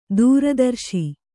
♪ dūra darśi